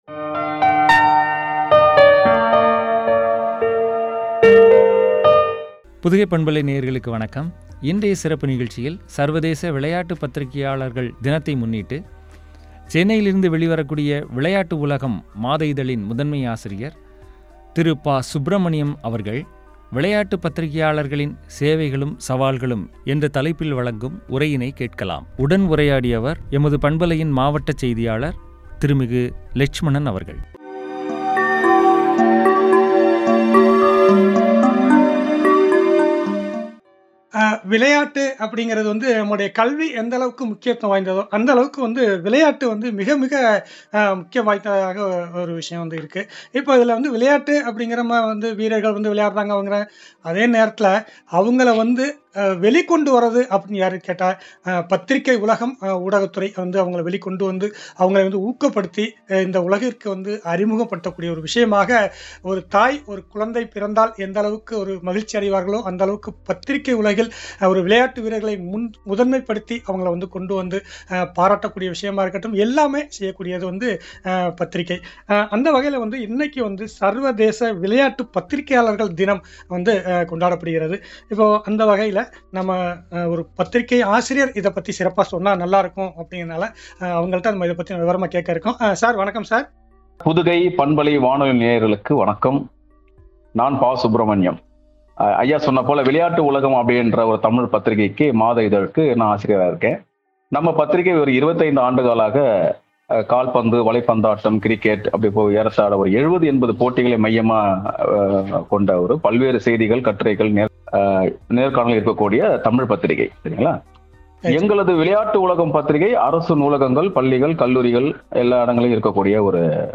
சவால்களும்” எனும் தலைப்பில் வழங்கிய உரையாடல்.